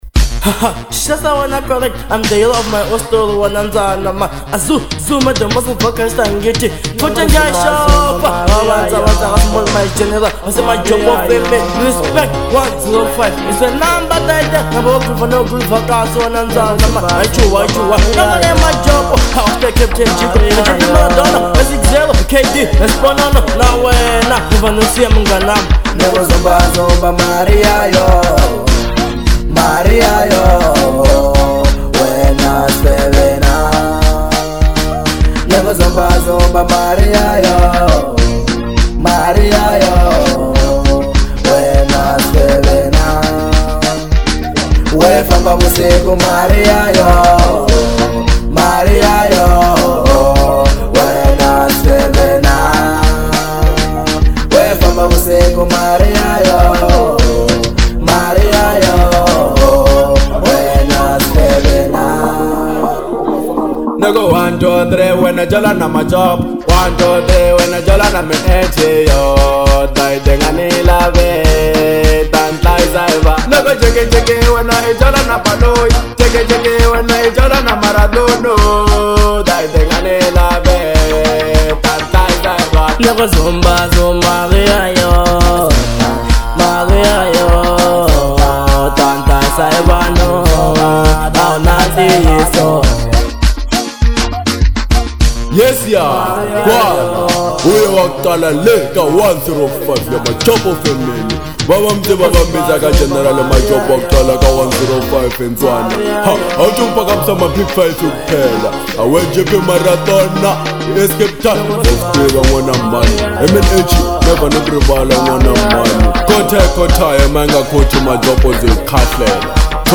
Genre : Manyalo